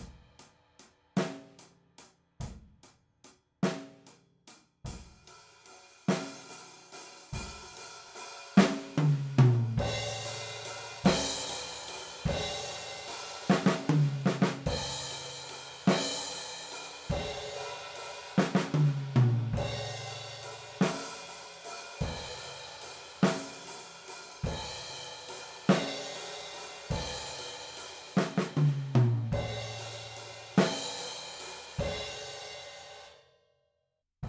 Klanglich wirklich sehr gut/vielseitig und nicht überzogen laut (bis auf das China, das fräst Dir wirklich die Ohren weg).
Overheads waren Oktava Kleinmembraner und als Raummikros 2 günstige t.bone Bändchen. Hier mal nur die Overheads und Raummikros komplett roh aus meinem Song zum Bluescontest: Dein Browser kann diesen Sound nicht abspielen.